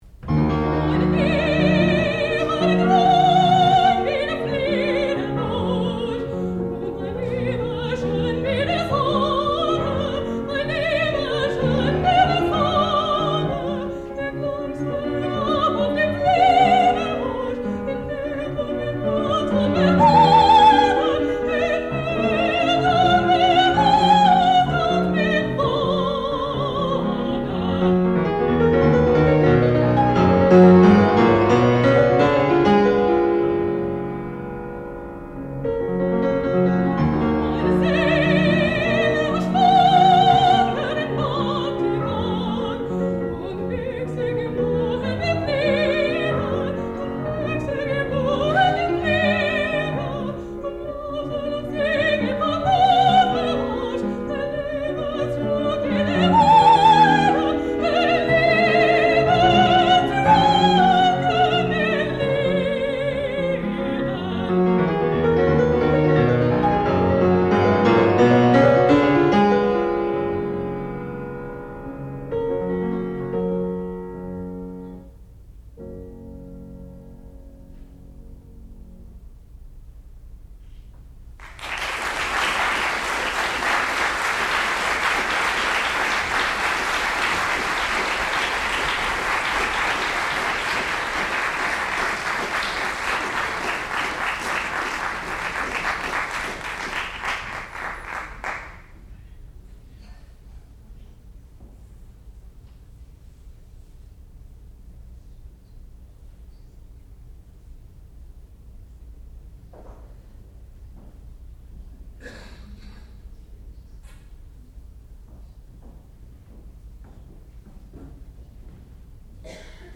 sound recording-musical
classical music
Qualifying Recital
soprano